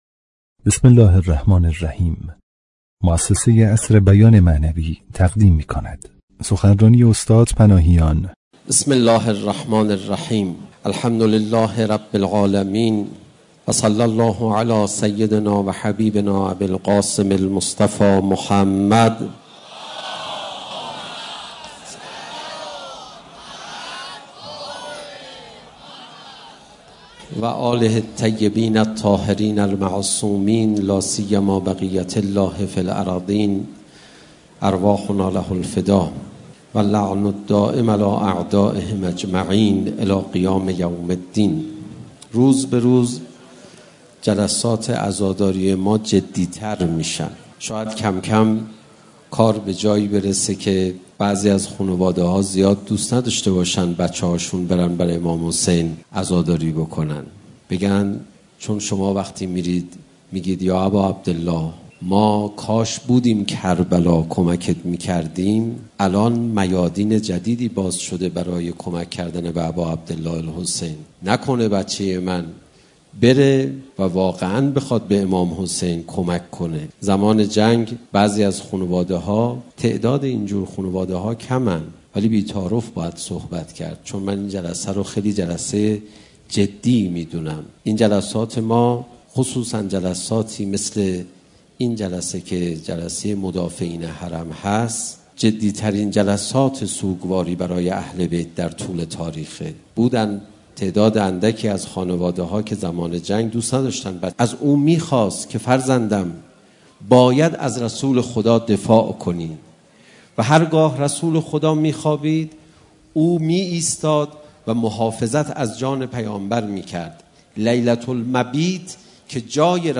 مکان: تهران - میدان امام حسین(ع) مناسبت: شانزدهمین اجتماع مدافعان حرم در روز شهادت امیرالمؤمنین(ع)